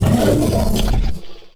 combat / creatures / cylarkan / he / attack1.wav
attack1.wav